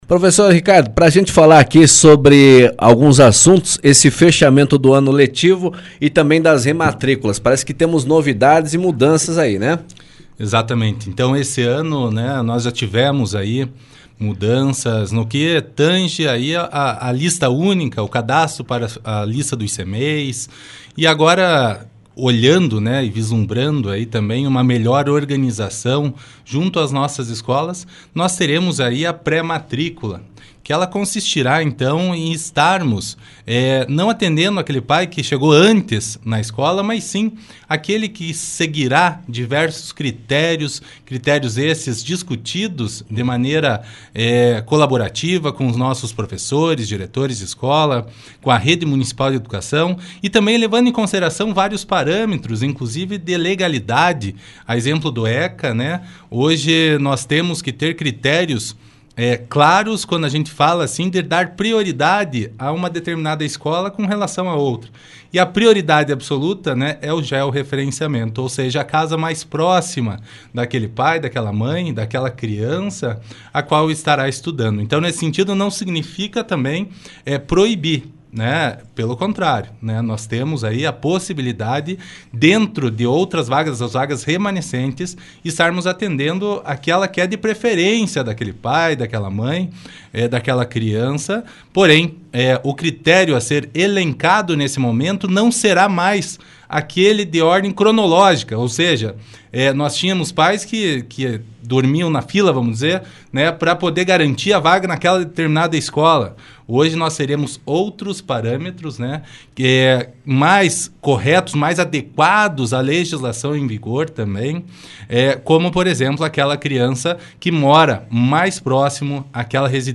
O Jornal Colmeia desta quinta-feira, 4, recebeu o secretário de Educação de União da Vitória, Ricardo Brugnago, que falou sobre as questões de mudança nas matrículas com o método de pré-matrícula, datas para rematrícula e cadastro na lista única para os Cmeis. Ele também trouxe um balanço em relação ao retorno das aulas presenciais de maneira híbrida que ocorreu no mês de agosto.